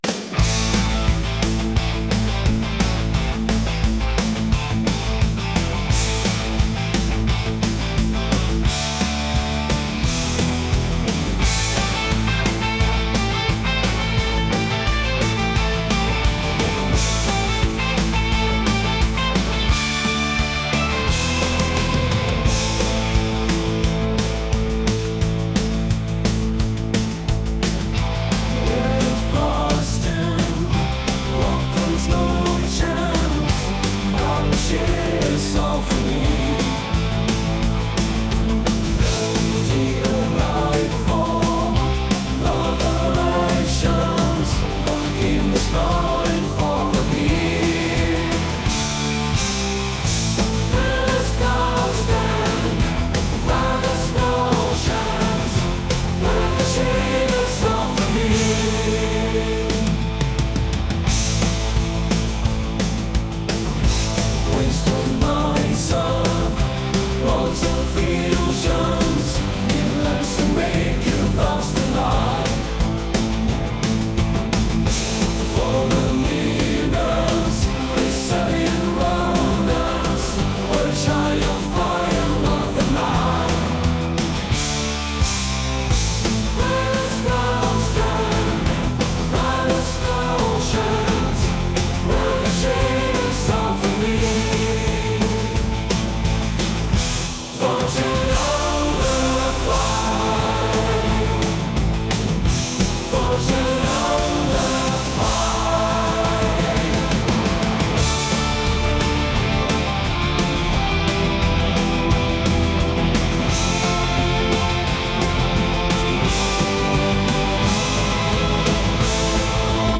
rock | indie | cinematic